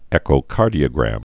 (ĕkō-kärdē-ə-grăm)